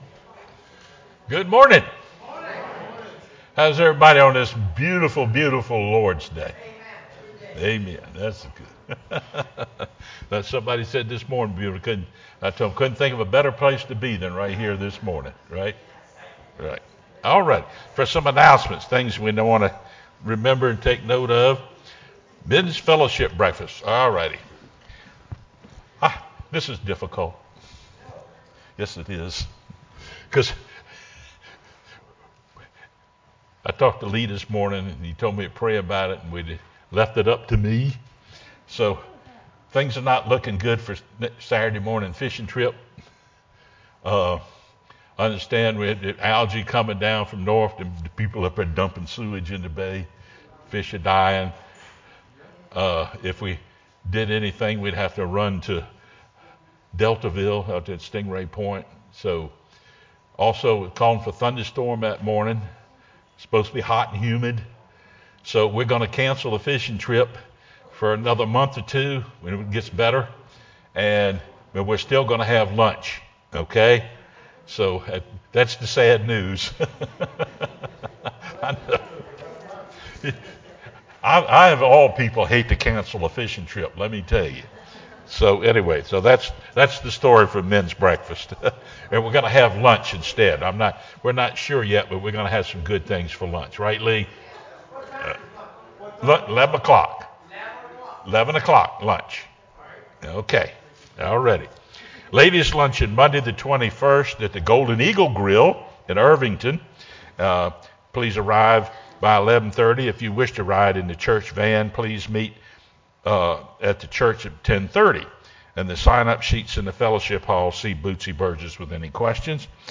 sermonJuly13-CD.mp3